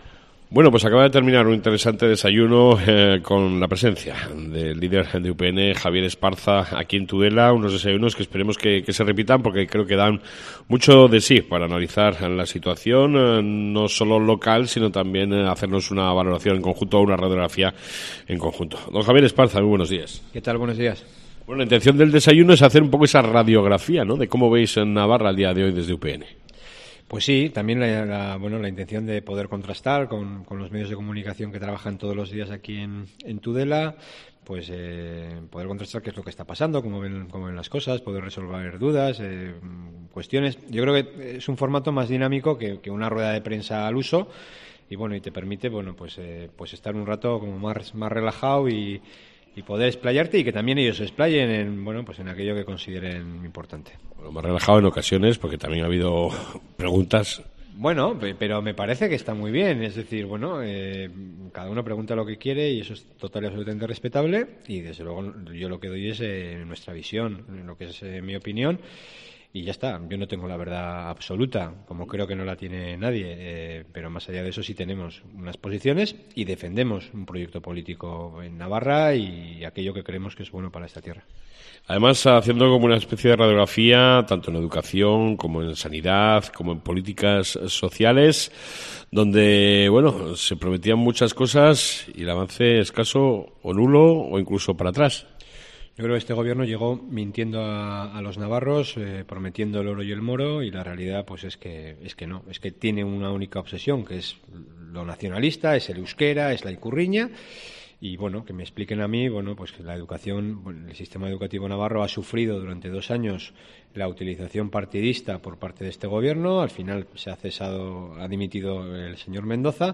Entrevista con el Presidente de UPN Javier Esparza en Cope Ribera